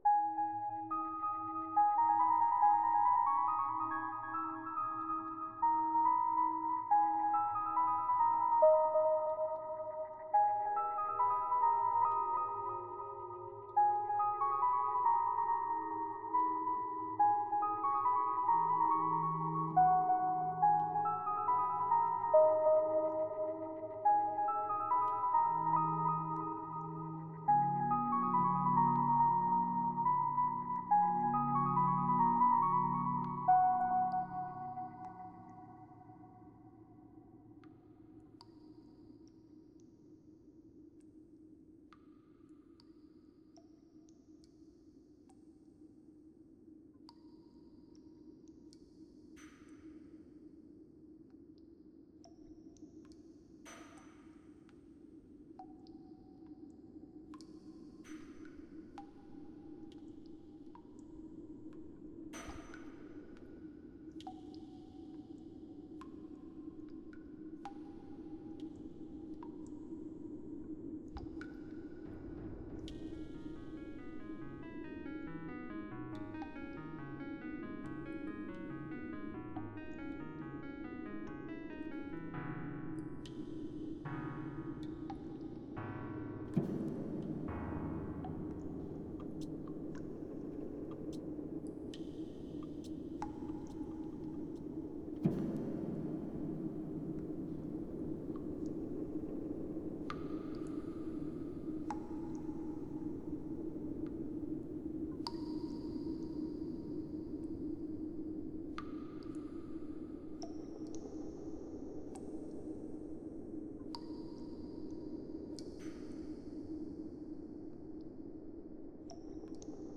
another little track mixed from ambient sounds available on OGA